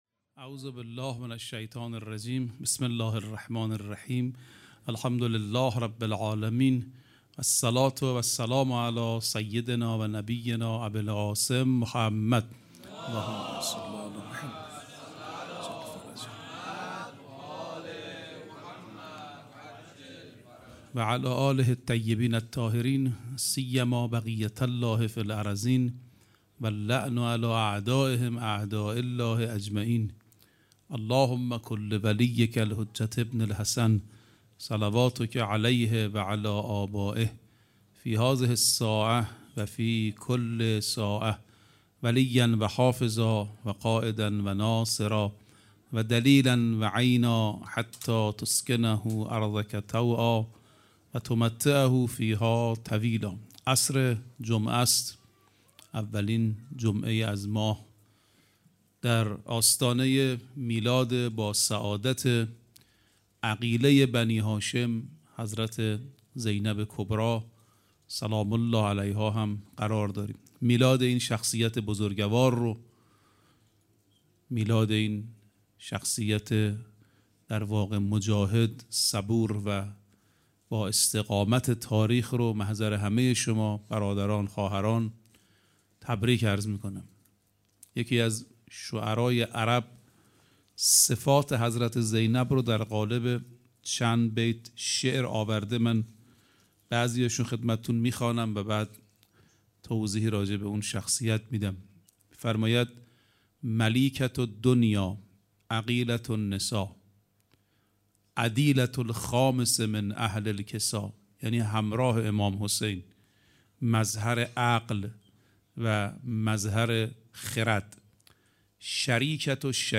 21 دی 97 - هیئت آل یاسین - سخنرانی